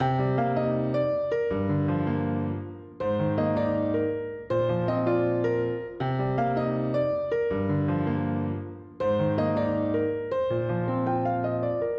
RNB钢琴80bpm的Amajor
Tag: 80 bpm RnB Loops Piano Loops 2.02 MB wav Key : A